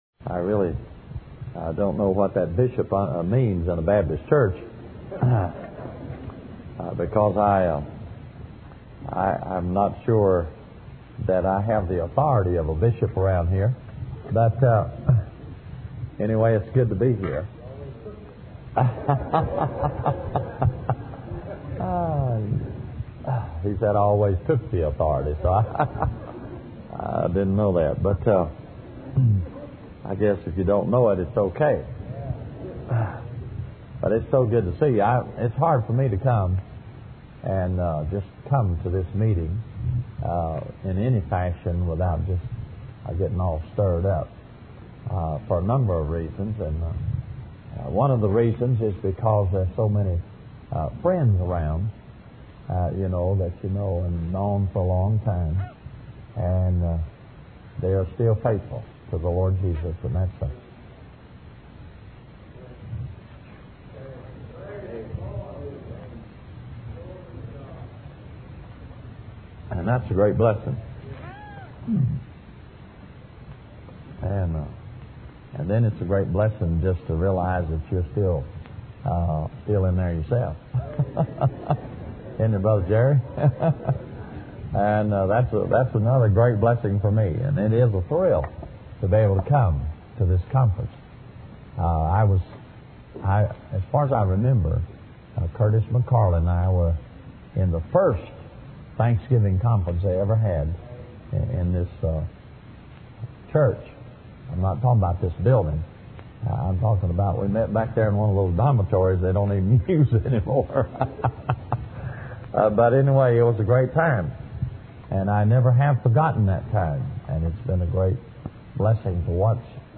In this sermon, the preacher emphasizes the importance of performing as a Christian and how to be the Christian one desires to be.